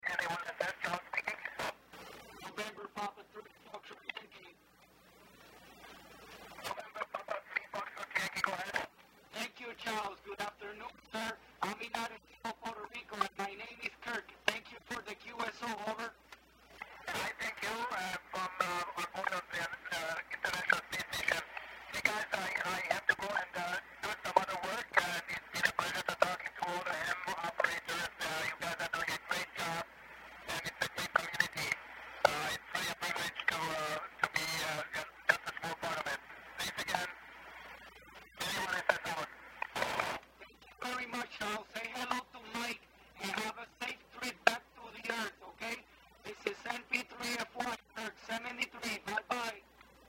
ISS Pass Over Puerto Rico & Charles Simonyi Was Calling Stations on Tuesday, April 6, 2009